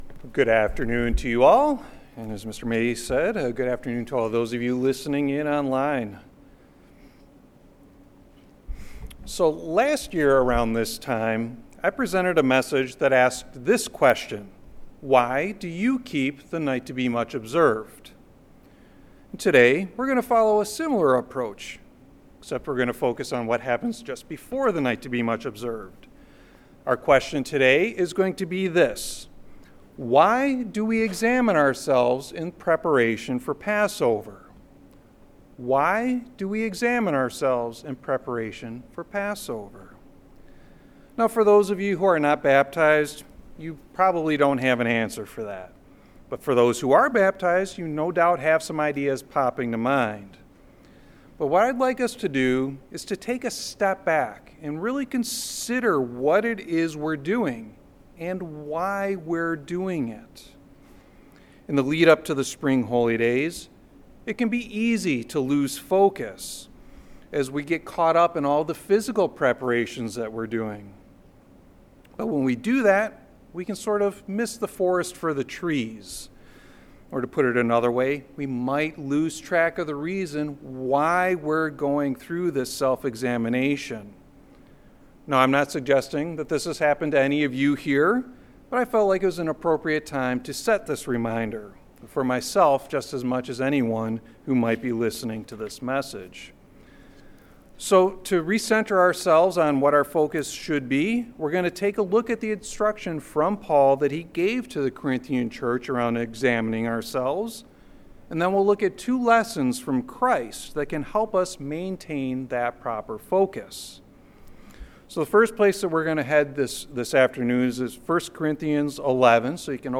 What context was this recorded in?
Given in Chicago, IL